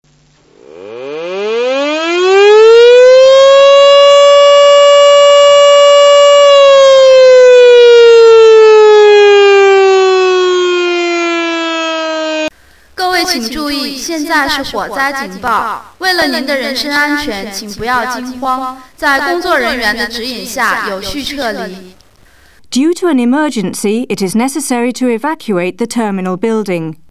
baojing.mp3